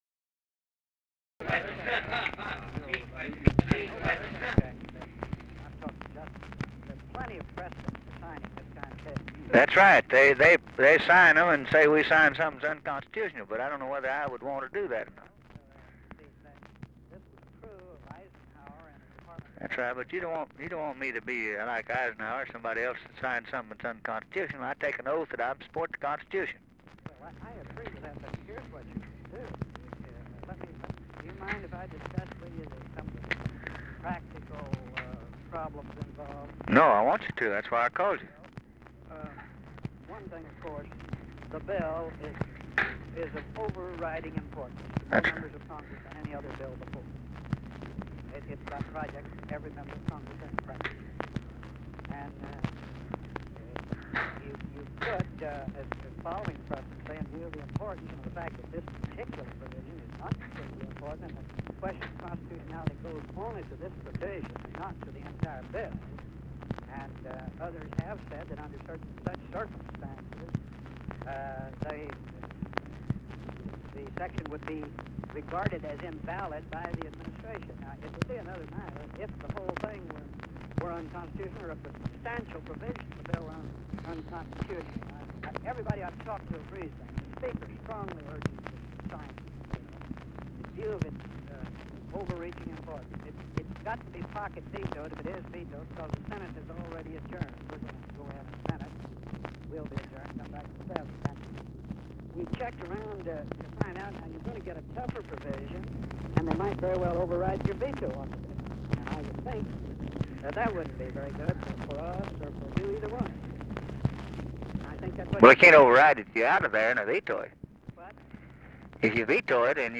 Conversation with CARL ALBERT
Secret White House Tapes